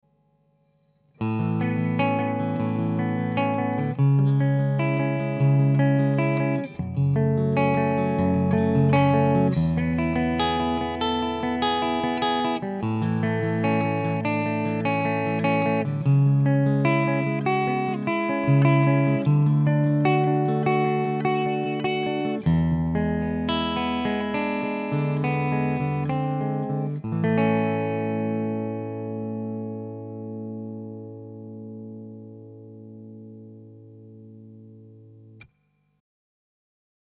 Bedingt durch die halbakustische Bauweise erwartet uns bei der Vox Bobcat V90 ein offener und warmer Sound, der durch die V90 Pickups allerdings in eine glockige und spritzig helle Richtung gebracht wird.
Voll aufgefahren klingt die Wildkatze knackig hell und ist damit toll für Blues-Rock Sounds geeignet. Dreht man den Tone weiter herunter zaubert die Bobcat einen tollen Jazz Sound – besonders auf dem Hals-Tonabnehmer.
Bei einem angezerrten Crunch Sound macht die Vox Bobcat V90 eine sehr gute Figur.
Vox Bobcat V90 Soundbeispiele
• Semiakustische E-Gitarre